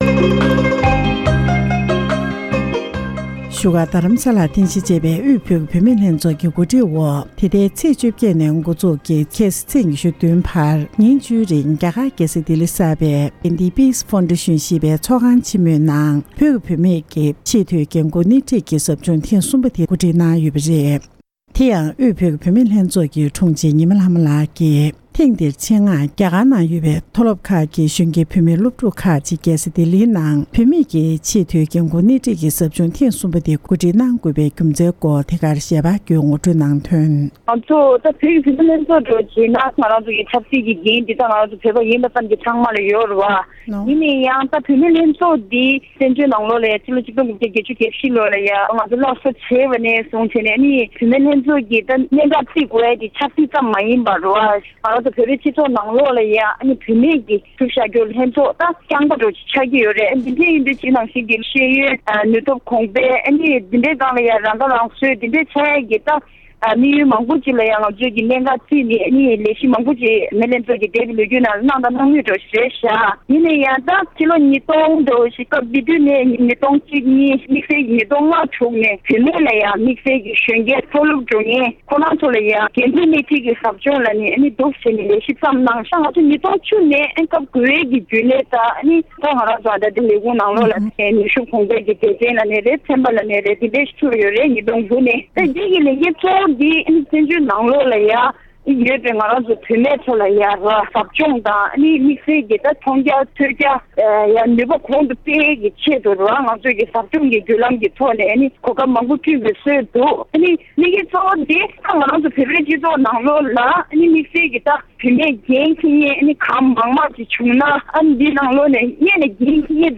འབྲེལ་ཡོད་མི་སྣར་ཞིབ་ཕྲའི་གནས་ཚུལ་བཀའ་འདྲི་ཞུས་པར་གསན་རོགས་ཞུ༎